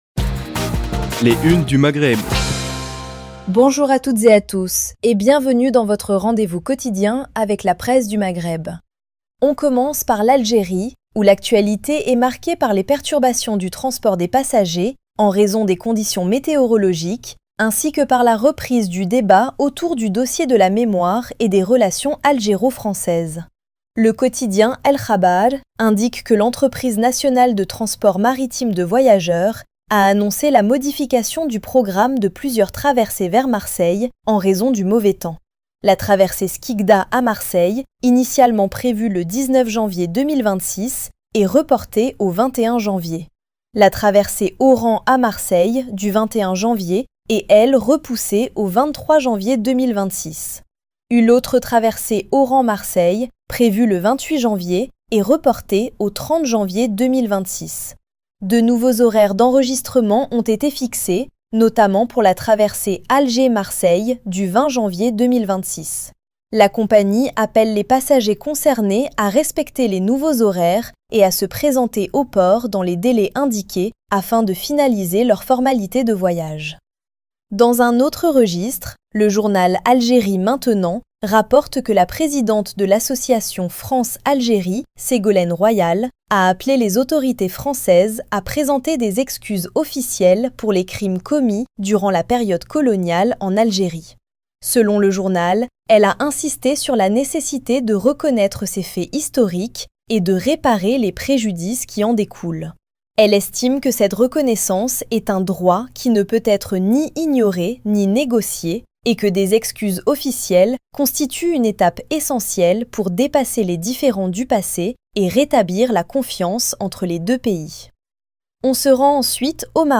Revue de presse des médias du Maghreb